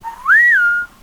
khanat-sounds-sources/_stock/sound_library/sfx/whistleling/highlight.wav at main